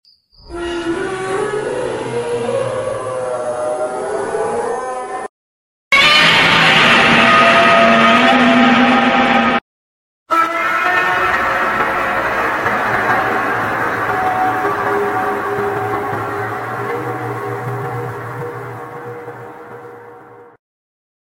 Shin Sonic Roars: Which Sounds sound effects free download